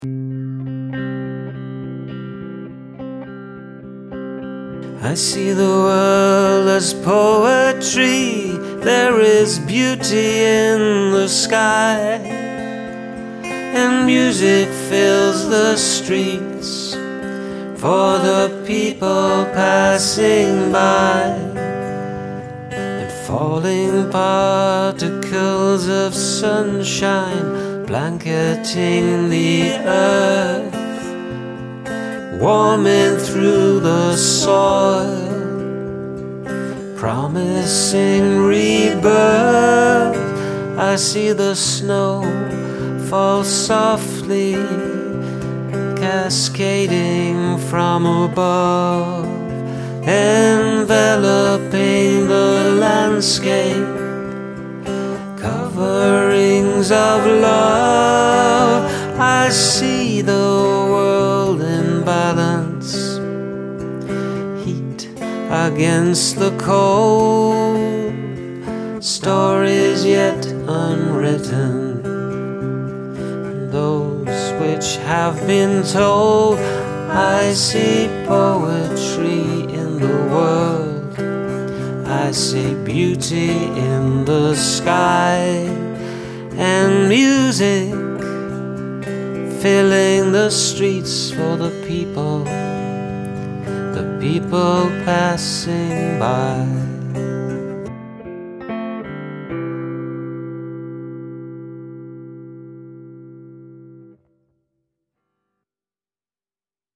This one has a very nice mood, message and melody.